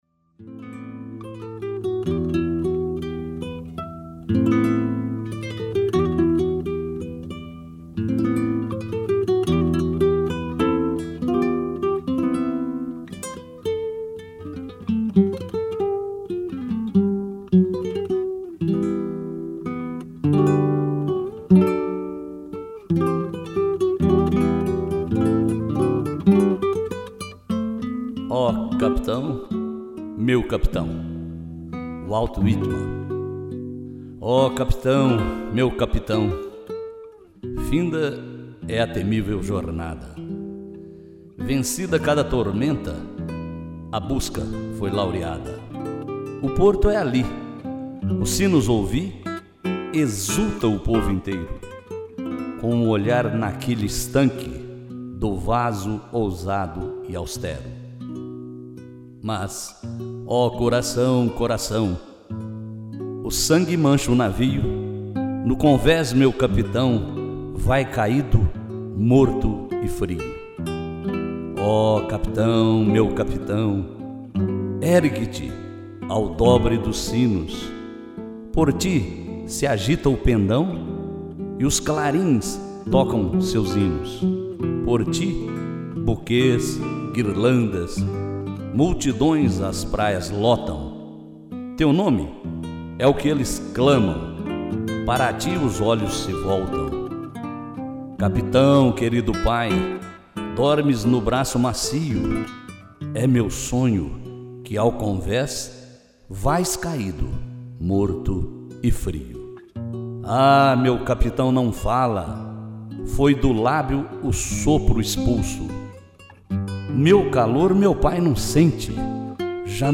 Ó Capitão! meu Capitão! - Walt Whitman - interpretação